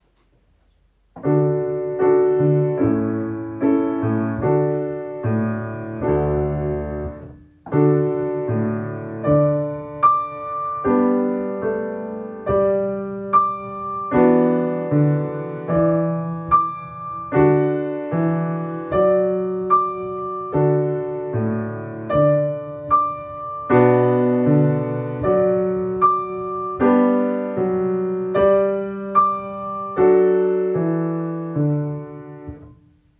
Beispiele Klavier
Streich_mit_viel_Zeit___Klavier.mp3